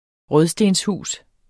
Udtale [ ˈʁœðˈsdensˌhuˀs ]